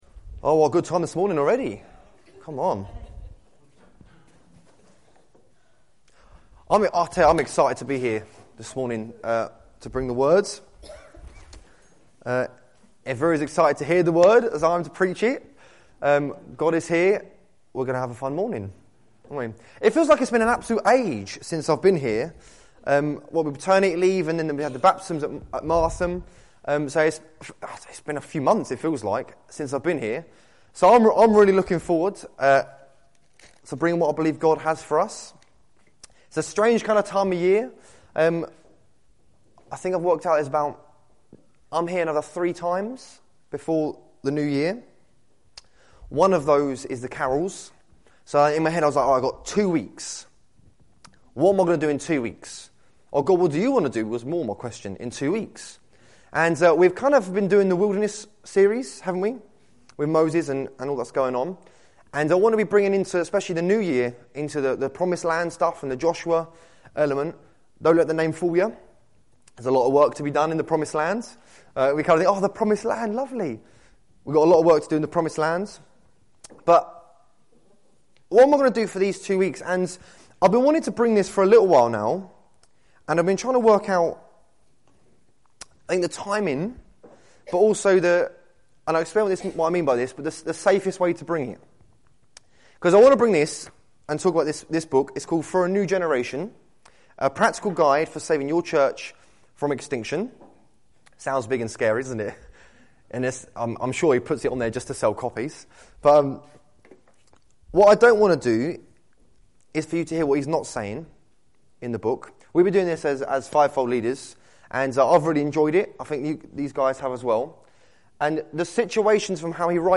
Exodus 13:21 Service Type: Sunday Morning « Isaiah 61